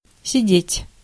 Ääntäminen
US : IPA : [sɪt]